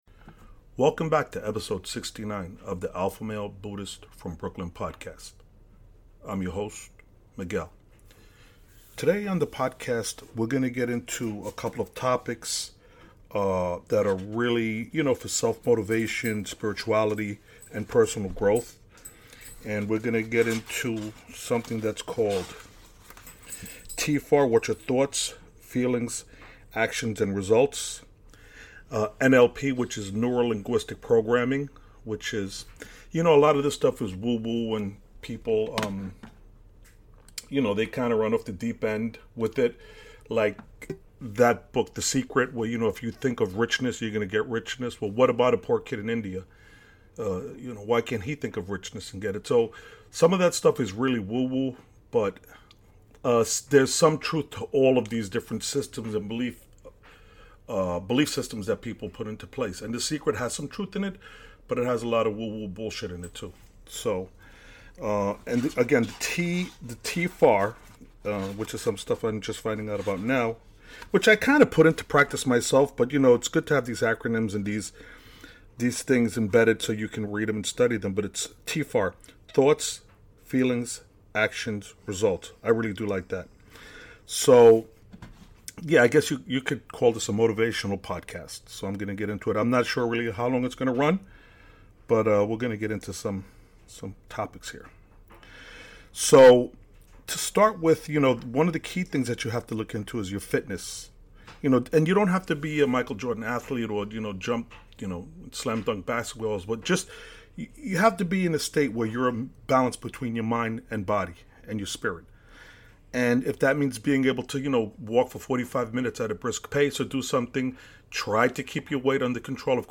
Ep 69- Motivational Talk - TFAR - NLP - Realize The Infinite Potential Within you - There is No such thing as Failure Just feedback - Take Action Now- Create your reality